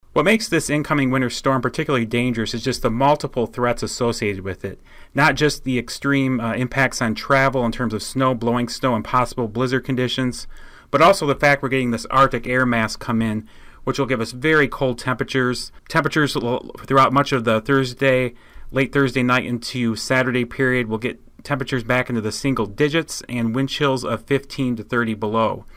During a National Weather Service multi media briefing on Wednesday morning, it was mentioned that this was a dangerous storm.